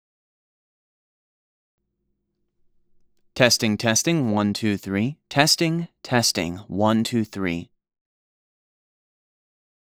The peak level of your audio sample is -0.1 dB.
Taking your audio sample as an example, the RMS (zero weighted) of the entire sample is -25.36 dB, but if we just select the actual audio (as shown below), the RMS measures -21.97 dB.
Your noise levels are “artificially low” and you might get rejected for too much processing/noise reduction.